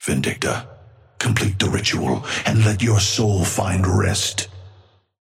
Amber Hand voice line - Vindicta, complete the ritual and let your soul find rest.
Patron_male_ally_hornet_start_03.mp3